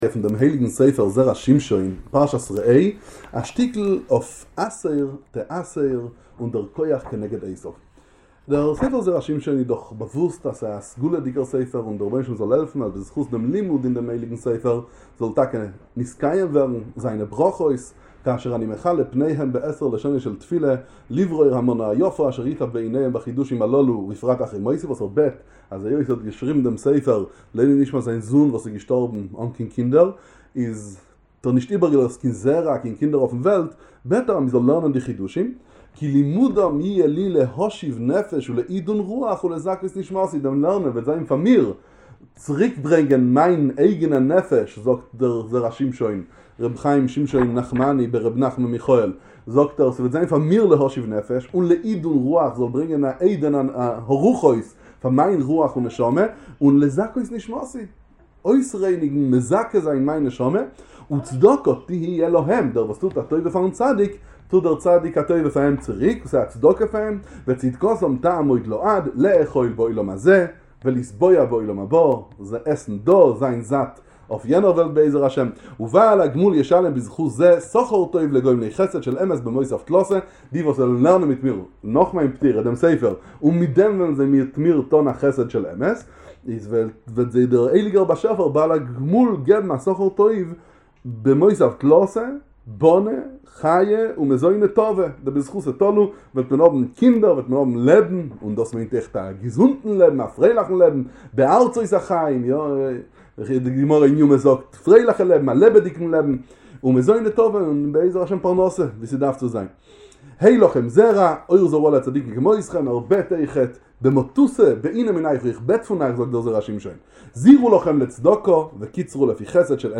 שיעור באידיש בספר המסוגל זרע שמשון על פרשת ראה